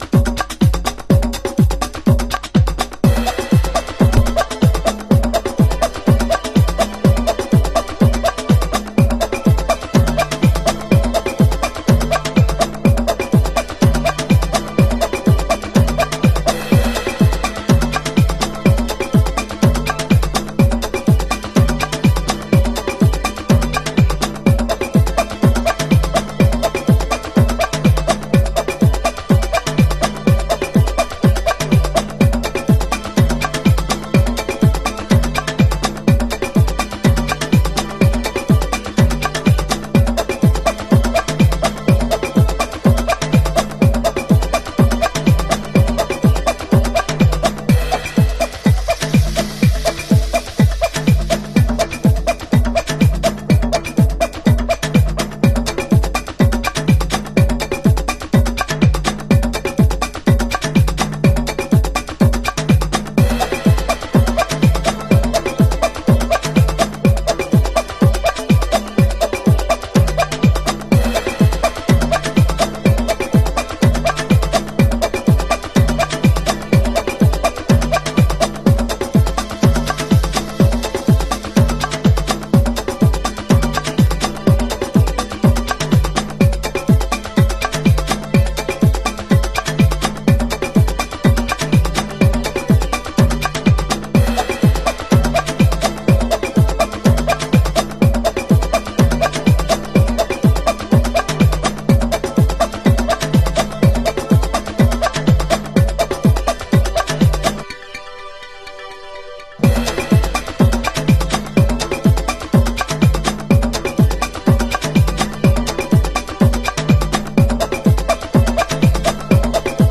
Chicago Oldschool / CDH
軽やかなアフリカン・パーカションにキッズ・チャントとくれば、コレは鉄板でしょう。